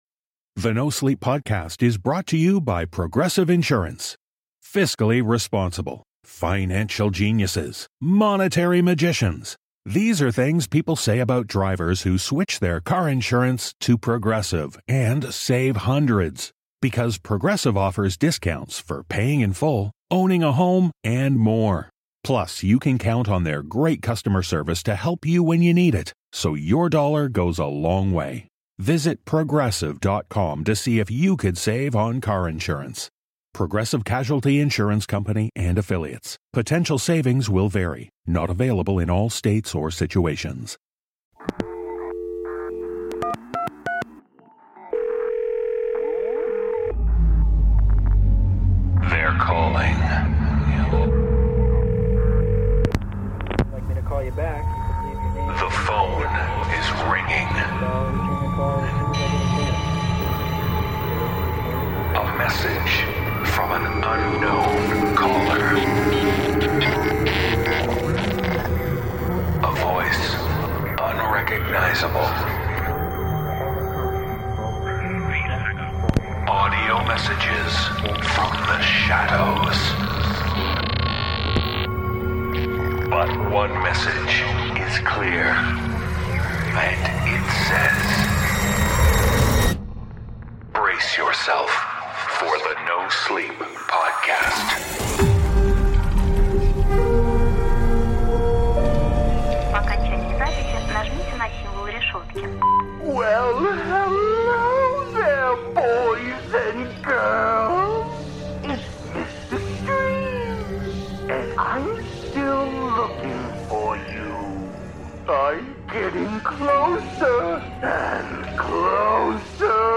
The voices are calling with tales of despicable darkness.